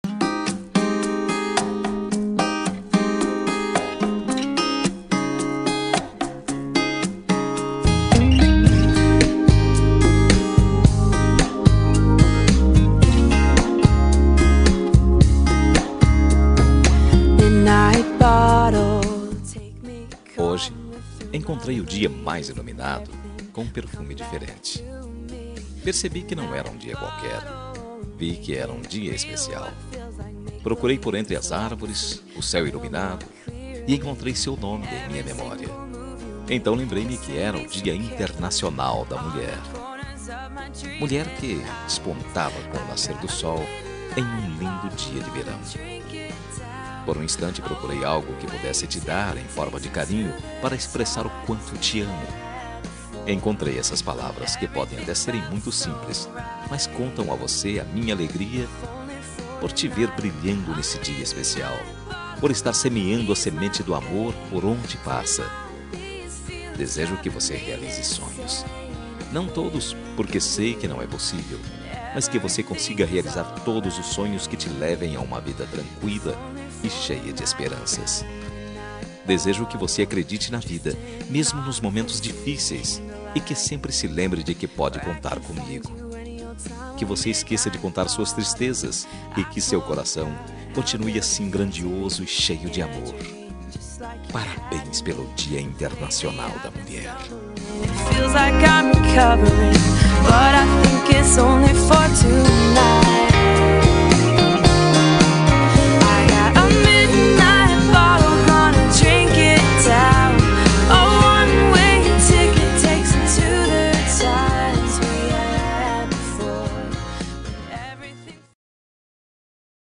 Dia das Mulheres Para Namorada – Voz Masculina – Cód: 53060